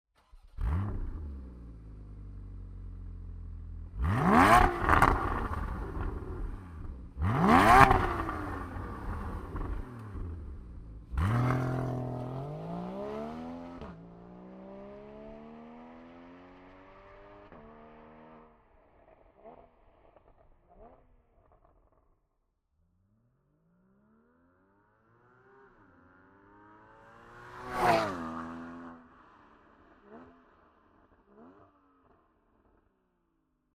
They feature a roaring, stonking and rip-snorting exhaust note.
981_gts_driving_sequence-1.mp3